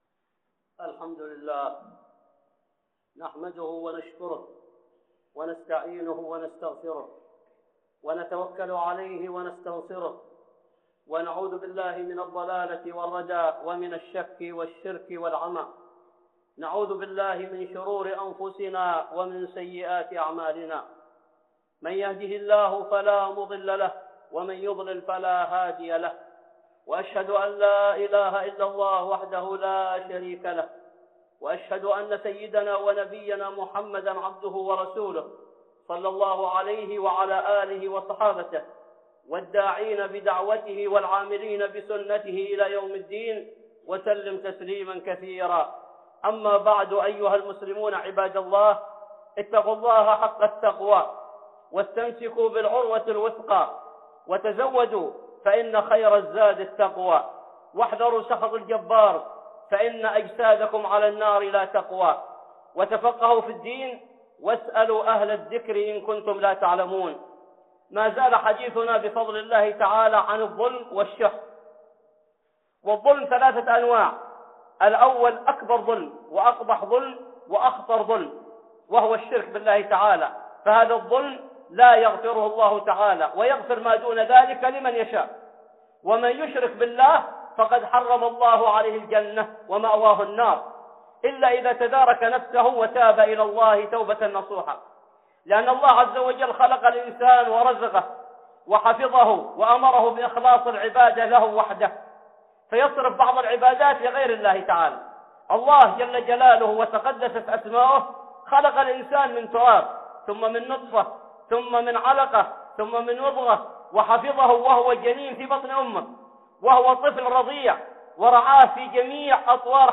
(خطبة جمعة) الظلم والشح 2